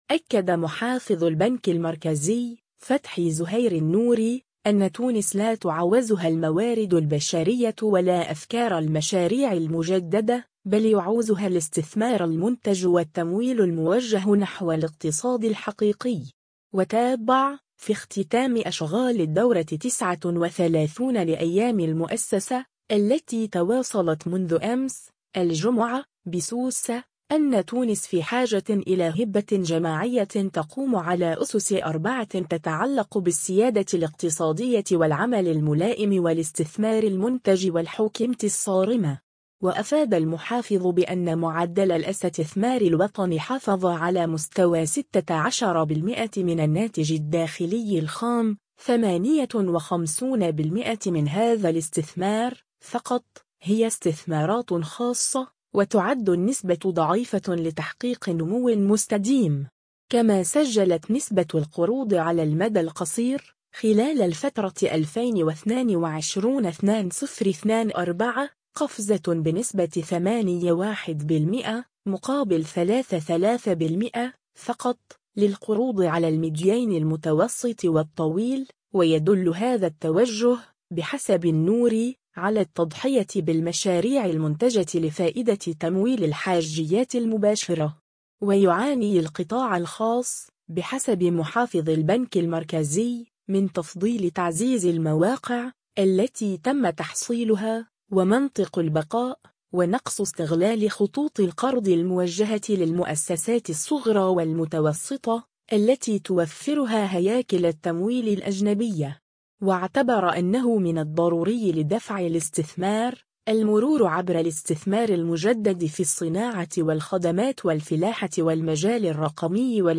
و تابع، في اختتام أشغال الدورة 39 لأيّام المؤسّسة، التّي تواصلت منذ أمس، الجمعة، بسوسة، أن تونس في حاجة إلى هبّة جماعية تقوم على أسس أربعة تتعلّق بالسيادة الاقتصادية والعمل الملائم والاستثمار المنتج والحوكمة الصارمة.
و توجه النوري في ختام كلمته، أمام حضور من رجال الأعمال وأصحاب المؤسسات وممثلي هياكل التمويل، وهياكل الدولة، بالقول “نحن على أهبة الاستعداد، ونعوّل عليكم”.